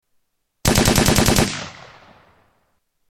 AK-47 Long burst